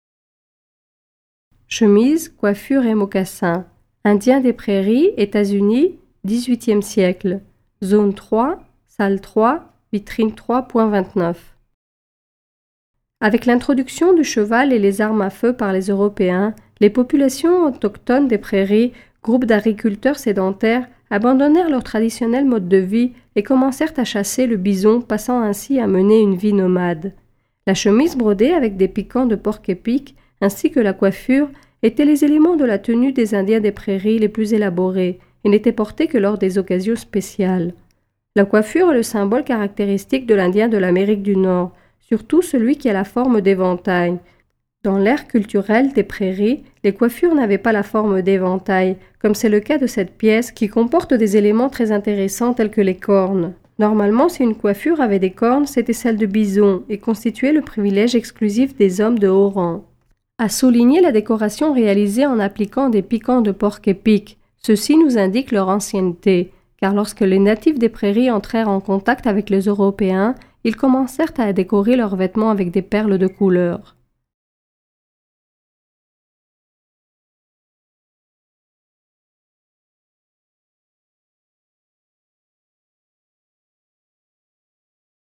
Audioguides par pièces